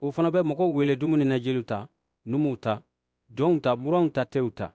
Synthetic_audio_bambara